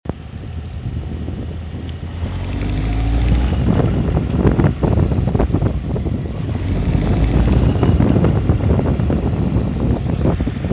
Klickern auf der rechten Seite am Zylinderkopf - Honda CB 1100 Forum
Hallo, habe seit einiger Zeit beim Beschleunigen auf der rechten Seite ein Geräusch als wenn ein Ventil klappert.
So, im Dateianhang ist eine MP3 in der ich das Geräusch mal aufgenommen habe, so gut es ging.